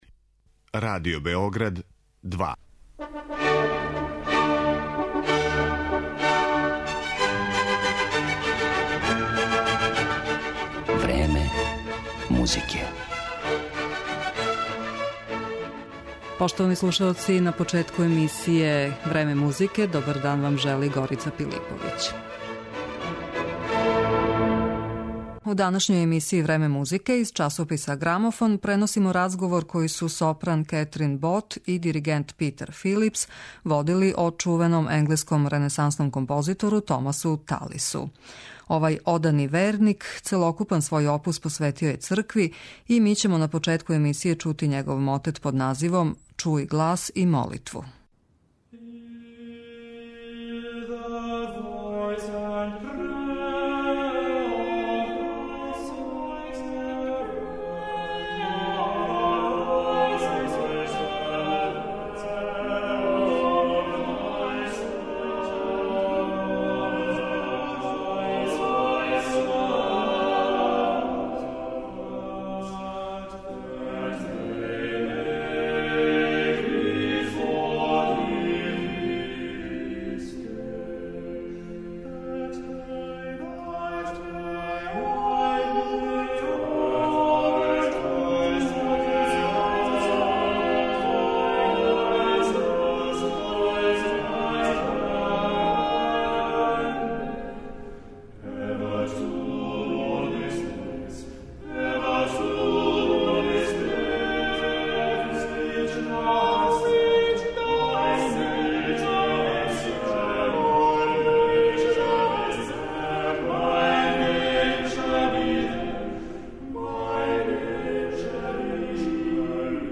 У данашњој емисији 'Време музике' из часописа 'Грамофон', преносимо разговор којег су сопран Кетрин Бот и диригент Питер Филипс, водили о чувеном енглеском ренесансном композитору Томасу Талису.